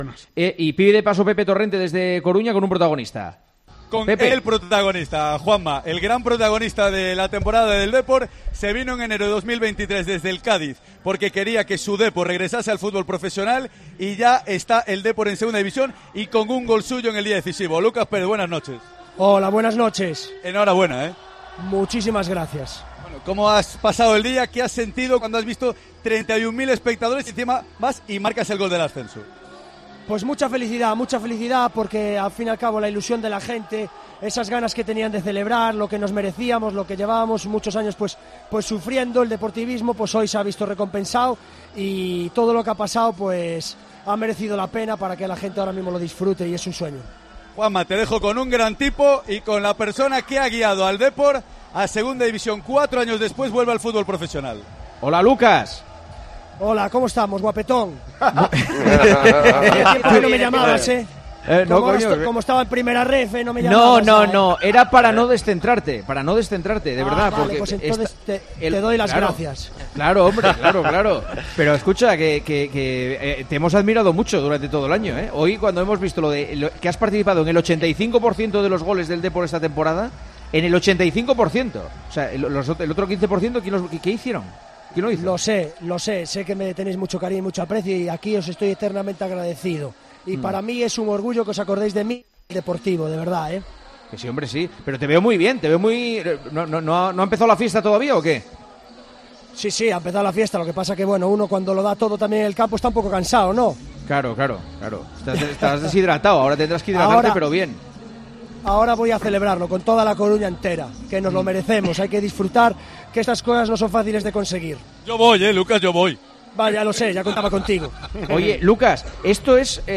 desde la fiesta coruñesa